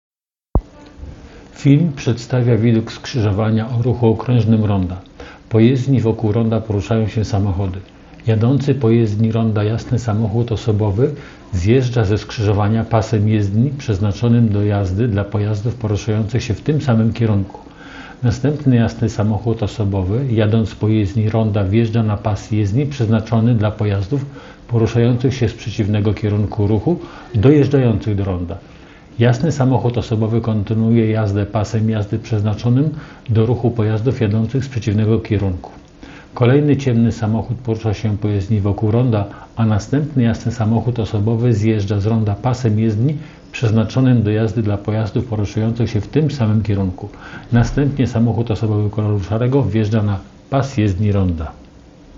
Nagranie audio Audiodeskrypcja do filmu wykroczenie na rondzie